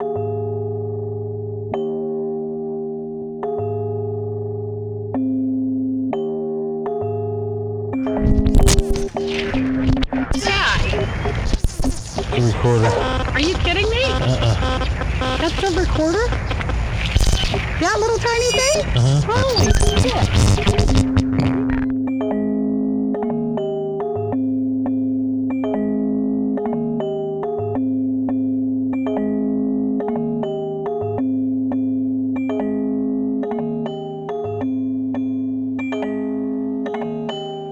vibra_intro.wav